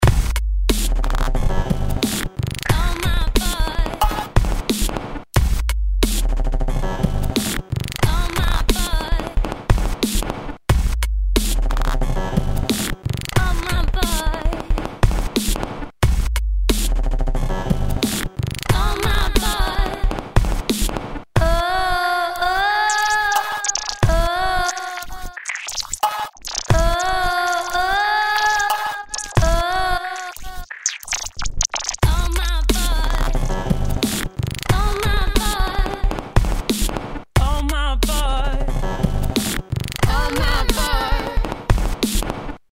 Nun aber zurück zu den Gesangsbearbeitungen, im nächsten Audiodemo zusammen mit dem Beat aus dem ersten Construction-Kit „Aglow“ und den soeben transformierten Single-Sounds.
Einige Gesangs- und Single-Sound-Loops habe ich mit dem Echo/Filter Effekt Timeless 2 von Fabfilter bearbeitet.